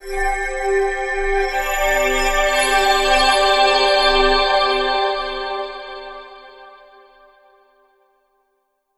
firewrks.wav